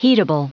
Prononciation du mot heatable en anglais (fichier audio)
Prononciation du mot : heatable